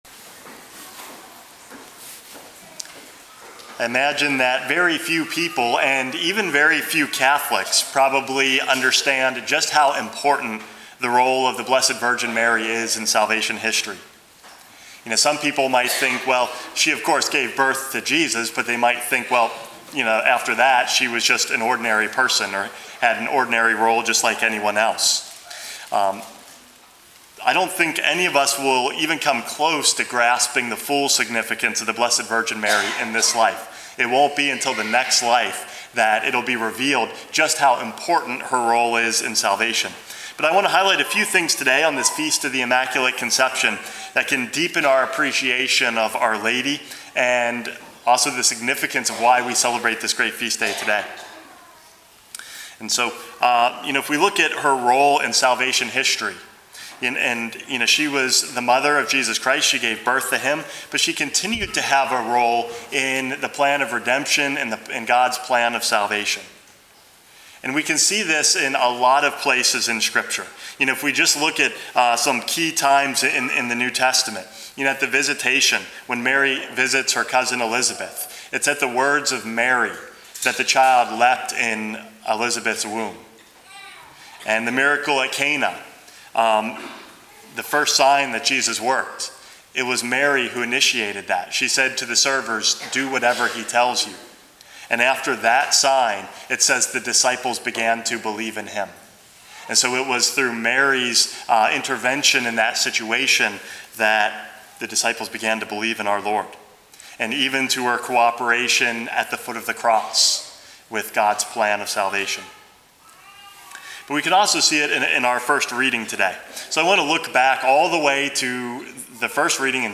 Homily #428 - The New Eve